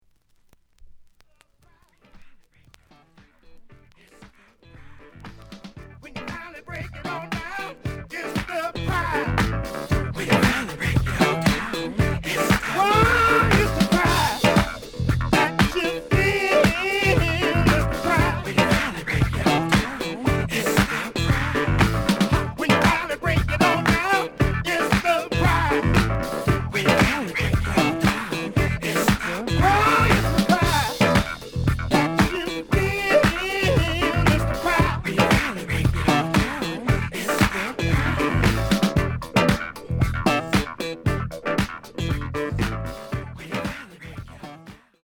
The listen sample is recorded from the actual item.
●Format: 7 inch
●Genre: Disco